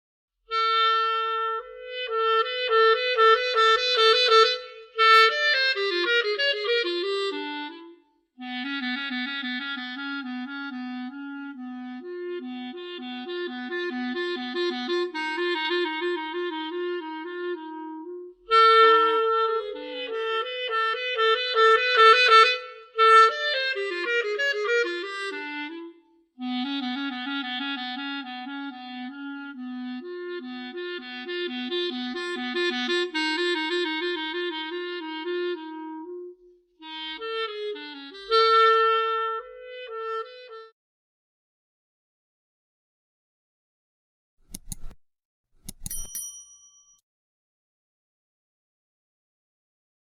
Sonido+De+Clarinete (audio/mpeg)
CLARINETE familia: viento madera